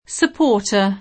vai all'elenco alfabetico delle voci ingrandisci il carattere 100% rimpicciolisci il carattere stampa invia tramite posta elettronica codividi su Facebook supporter [ingl. S ëp 0 otë ; italianizz. S upp 0 rter ] s. m.; pl. (ingl.) supporters [ S ëp 0 otë @ ]